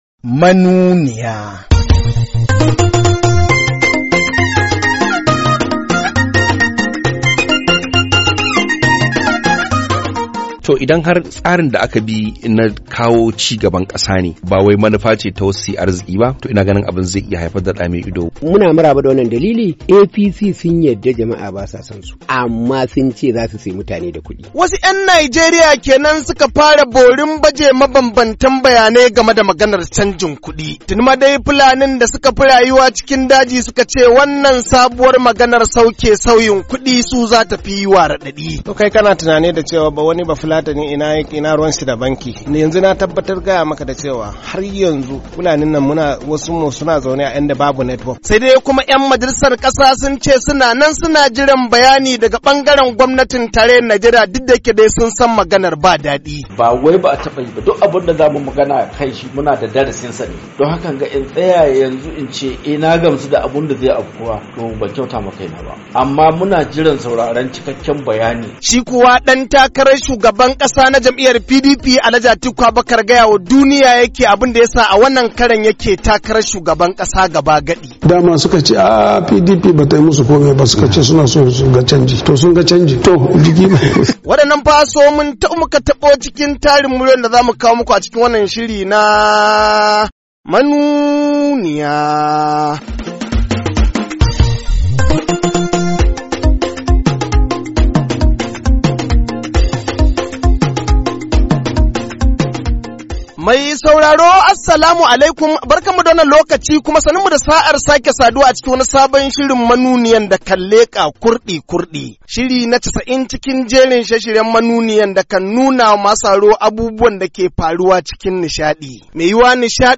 KADUNA, NIGERIA - A cikin shirin na wannan makon mun maida hankali ne kan shirin canjin kudi a Najeriya da kuma hirar dan-takarar shugaban kasa na Jam'iyyar PDP, Alhaji Atiku Abubakar kan batutuwa daban-daban.